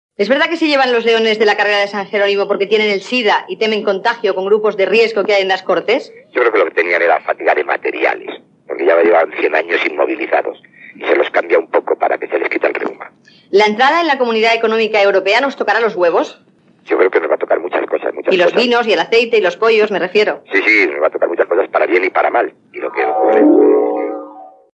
Preguntes ràpides al polític socialista i jurista Pablo Castellano
Info-entreteniment